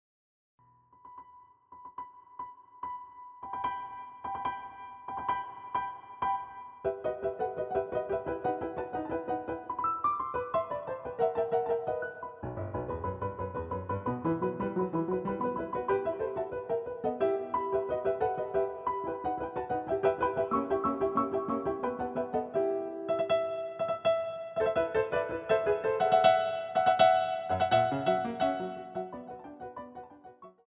A quality audio recording of an original piano roll